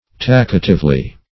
[1913 Webster] -- Talk"a*tive*ly, adv. --
talkatively.mp3